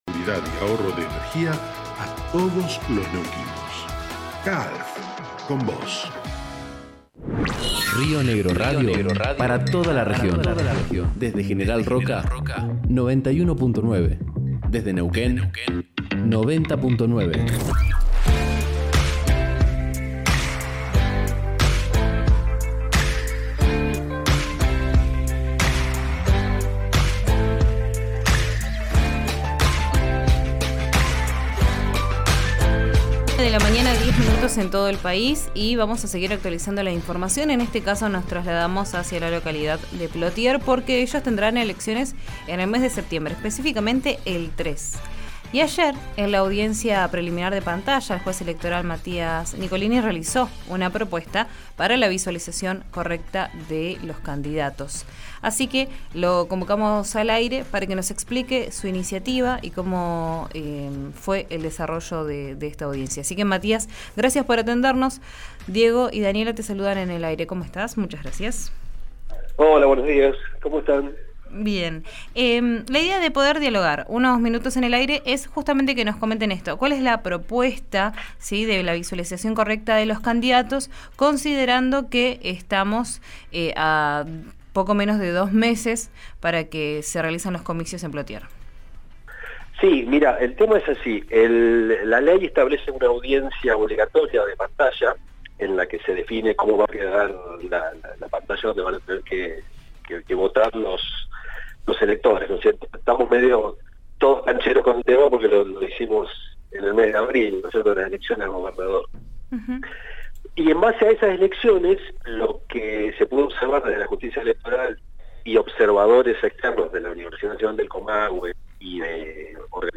Escuchá al Juez Electoral, Matías Nicolini, en RÍO NEGRO RADIO: